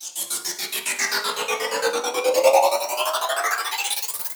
Transition [Only Gamerz].wav